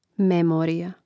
mèmōrija memorija